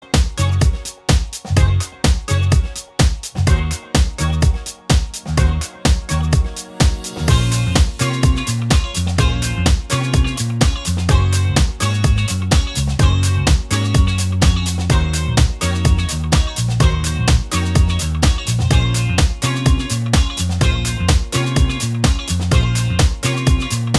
Main All Guitars Pop (2000s) 4:01 Buy £1.50